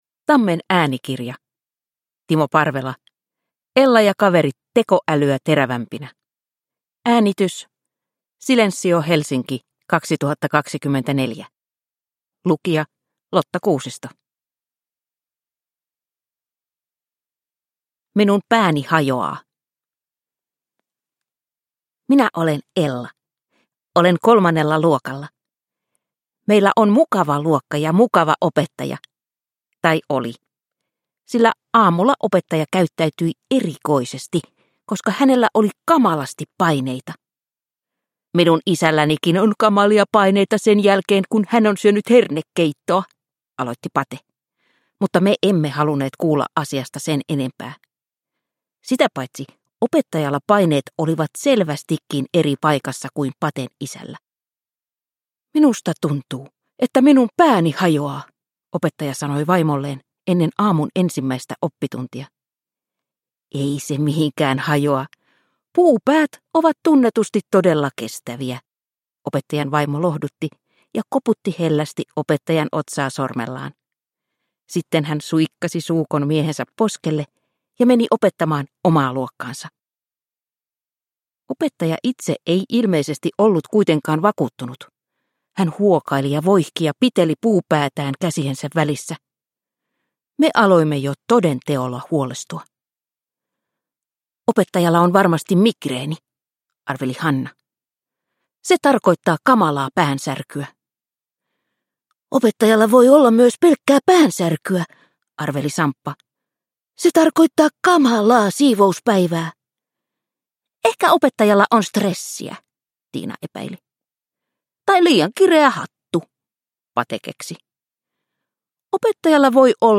Ella ja kaverit tekoälyä terävämpinä – Ljudbok